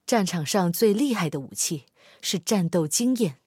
SU-122AMVP语音.OGG